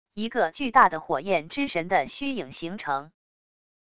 一个巨大的火焰之神的虚影形成wav音频